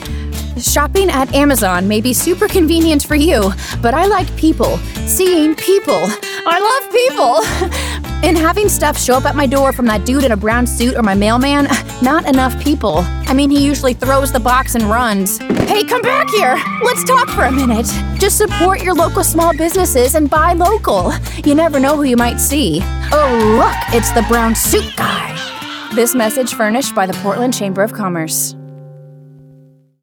Natürlich, Urban, Warm
Persönlichkeiten